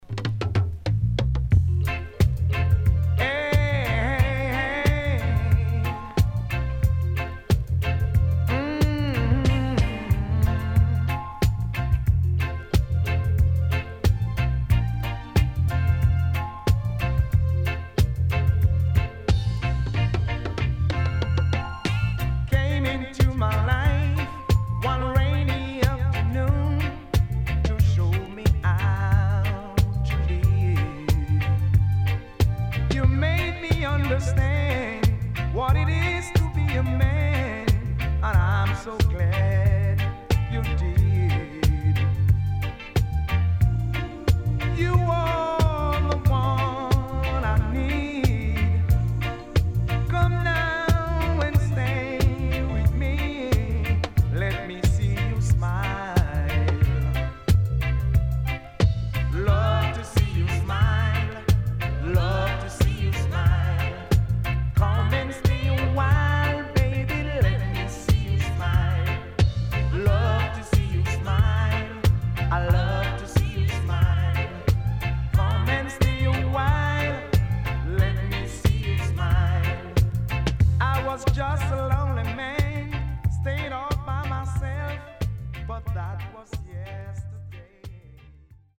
CONDITION SIDE A:VG(OK)〜VG+
【12inch】
SIDE A:うすいこまかい傷ありますがノイズあまり目立ちません。